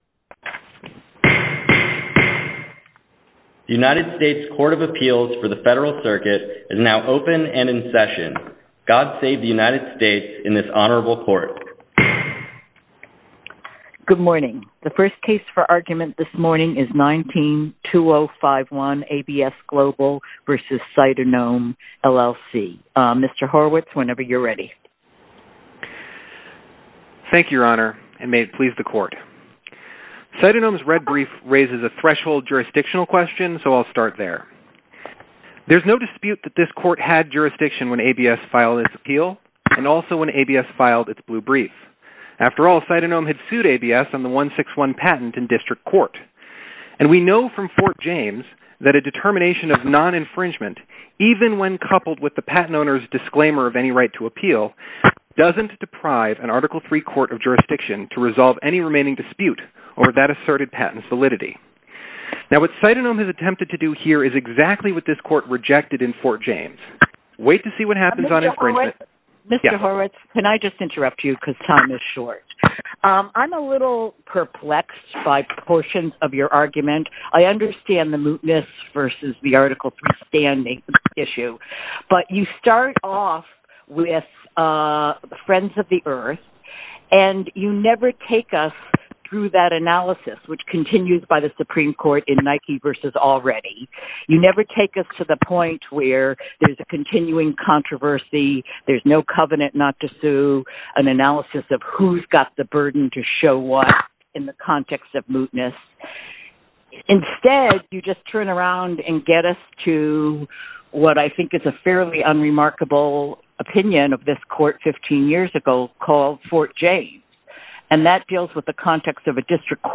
Federal Circuit Oral Argument AI Transcripts